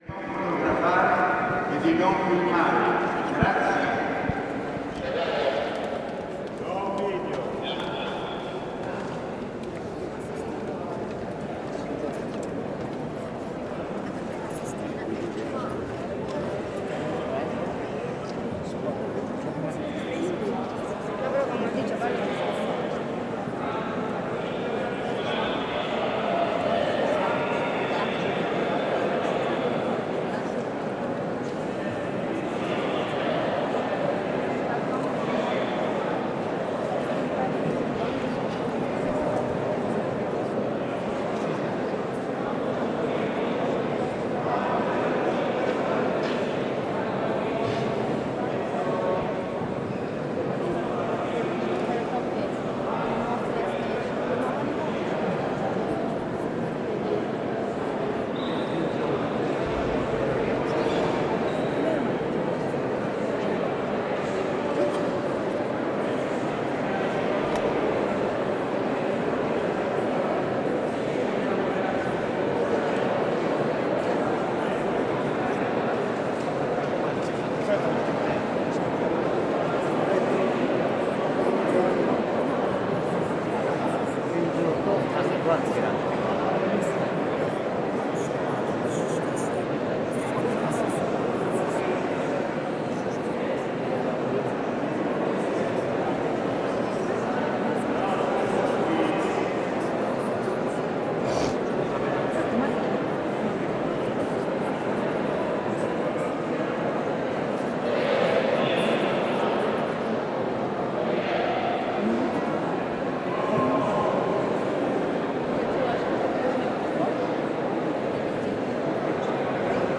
The sound from the interior of the Sistine Chapel. Every so often a voice declares through a PA "Silenzio" and "Silence", followed by exhortations not to take photos. Each time silence is declared, the noise level dips respectfully, but over the course of the following minutes builds back up in a crescendo of chatter.